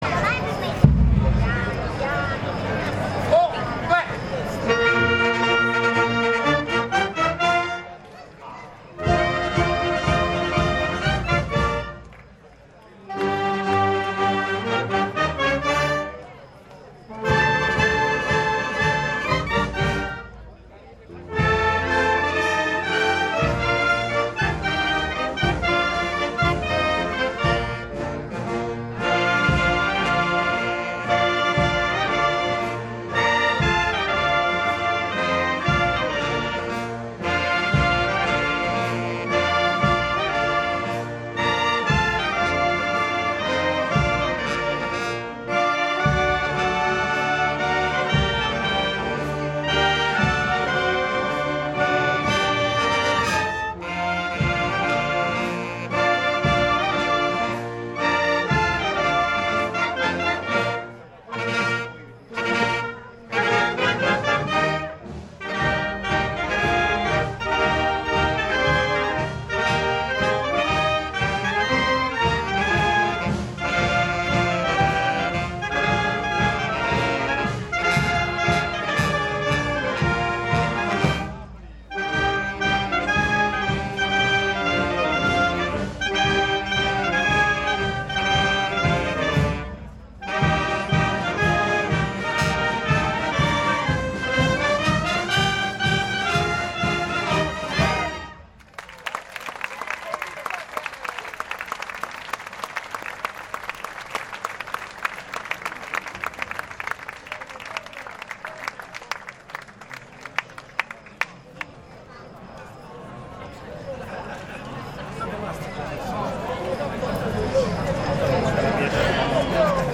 La kermesse è proseguita con l’amalgama delle quattro bande presenti che si sono prodotte in ciò che è ascoltabile ora.
Qui, volente o nolente e non è per forza un difetto, si sente la differenza di concezione di ogni gruppo bandistico.
Tutti Insieme, Inno Nazionale E Fuochi D’Artificio
Tutto si è chiuso a base di inno nazionale e fuochi d’artificio,
13Tutti-Insieme-Inno-Nazionale-E-Fuochi-DArtificio.mp3